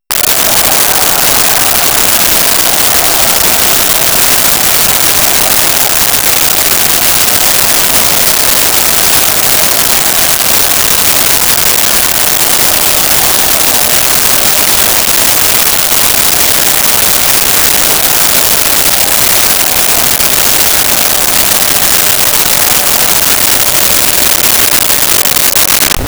Yelling Soldiers Group
Yelling Soldiers Group.wav